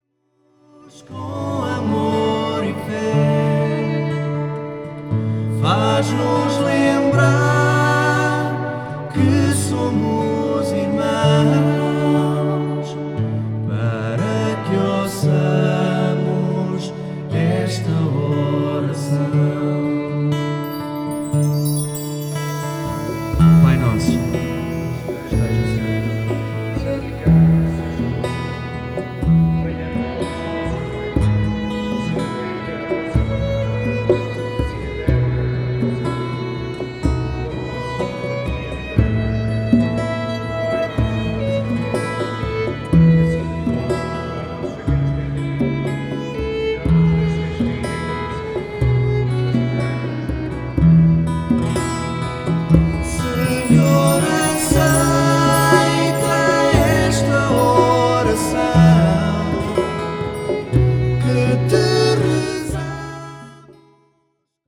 Caracterizamo-nos pela sonoridade jovem e elegante, marcada pelo requinte melódico do violino e pela harmonia suave das vozes.